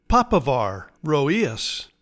Pronounciation:
Pa-PA-ver row-EE-as